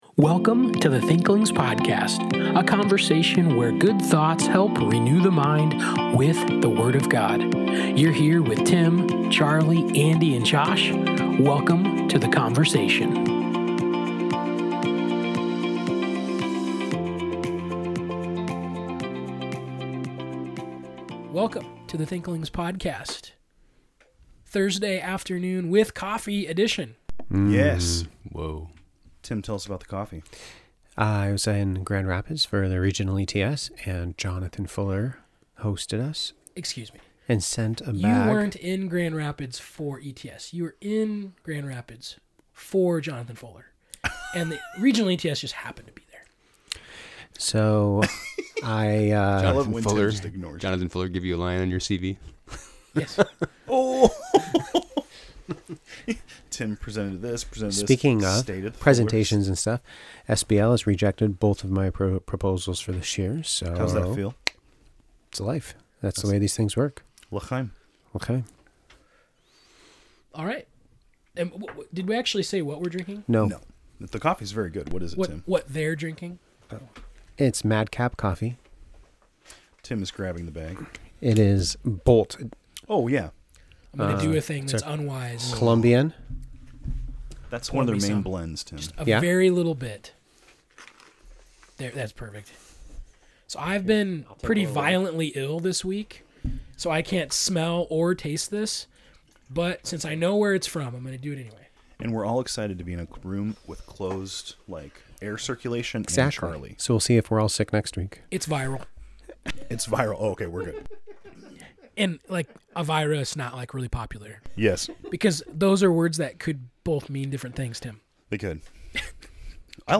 Thanks for tuning in to this week’s conversation!